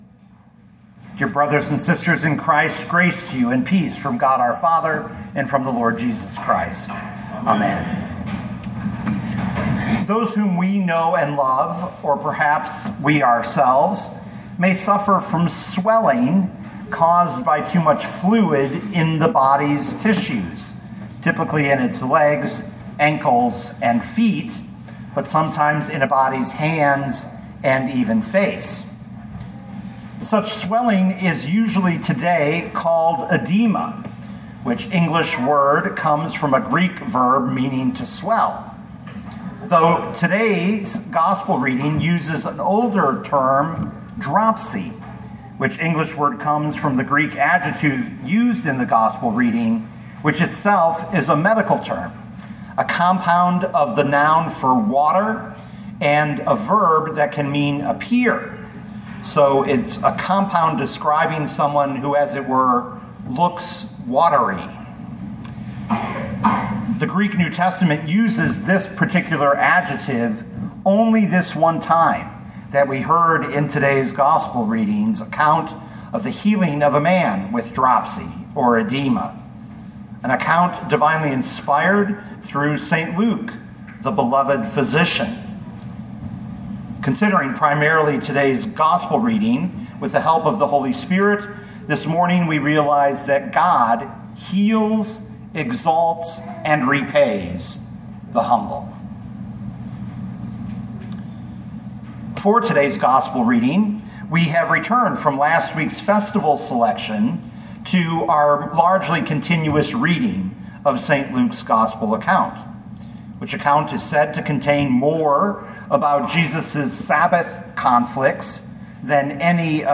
2025 Luke 14:1-14 Listen to the sermon with the player below, or, download the audio.